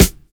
DISCO 2 SD2.wav